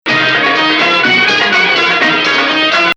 Bayati 1